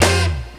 HORN HIT 4.wav